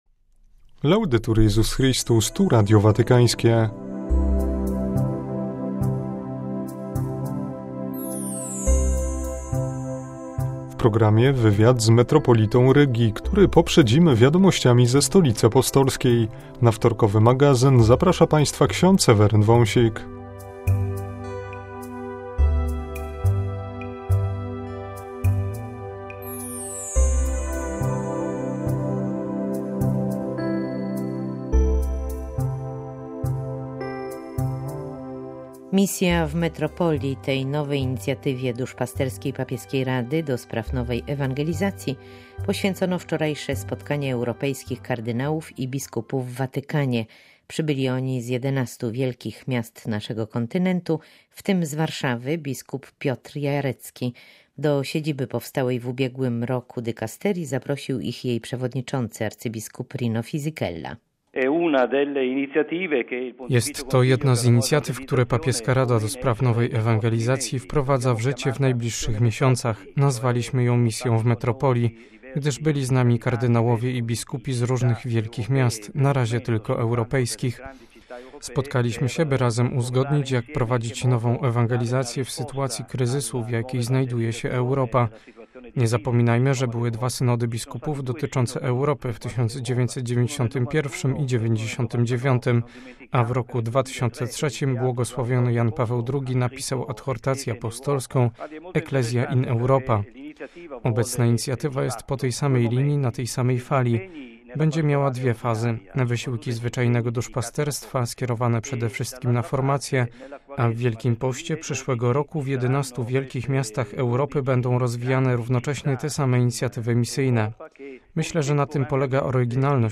Home Archivio 2011-07-12 17:41:08 Magazyn we wtorek W programie: wywiad z metropolitą Rygi abp. Zbigniewem Stankiewiczem, który poprzedzamy aktualnościami watykańskimi.